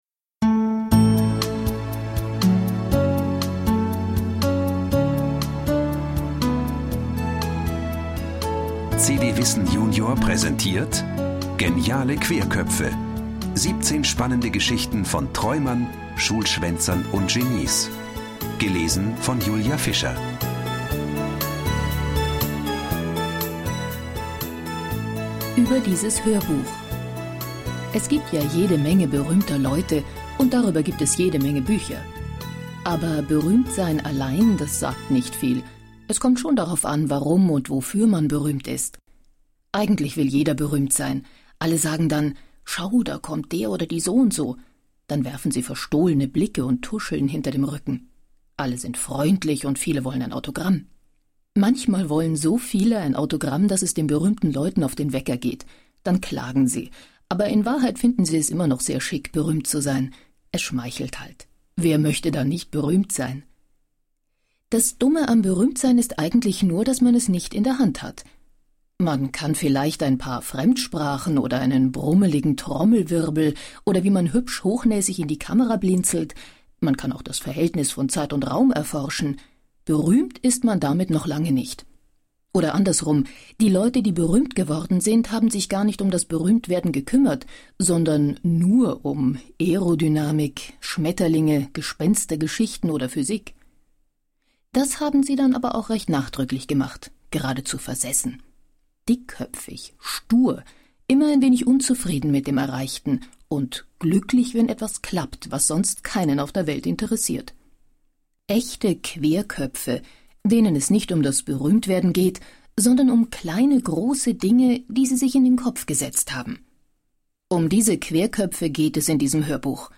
Schlagworte Audio-CD • Audio-CD, Kassette / Kinder- und Jugendbücher/Biografien • AUDIO/Kinder- und Jugendbücher/Biografien • Berühmte Personen • Biografien/Erinnerungen; Kindersachbuch/Jugendsachbuch • Biografien/Erinnerungen; Kindersachbuch/Jugendsachbuch (Audio-CDs) • Biografien; Kindersachbuch/Jugendsachbuch (Audio-CDs) • Biographien • Geschichte • Hörbücher • Hörbuch für Kinder/Jugendliche • Hörbuch für Kinder/Jugendliche (Audio-CD) • Hörbuch; Lesung für Kinder/Jugendliche • Kinder-CDs (Audio) • Querköpfe